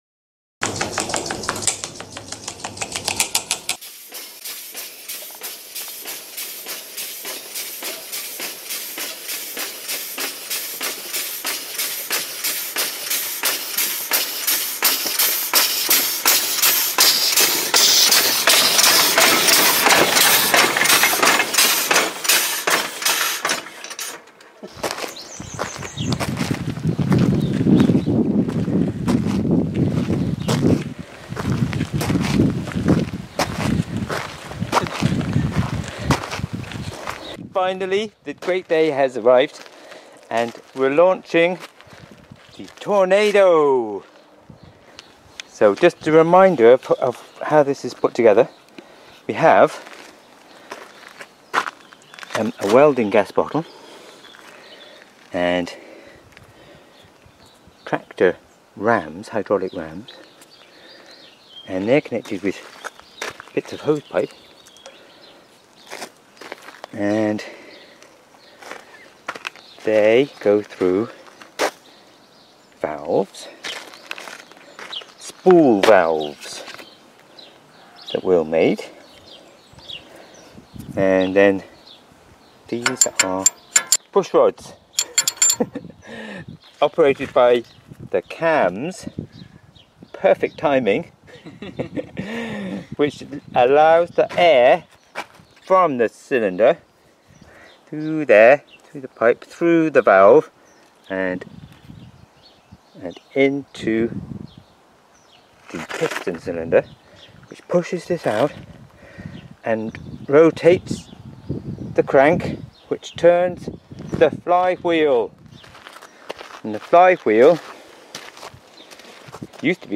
First Run - Home-Made Compressed Air Locomotive For The Field Railway